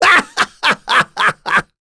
Baudouin-Vox_Happy3.wav